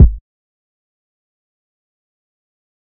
Kick (HardShit).wav